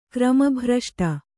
♪ krama bhraṣṭa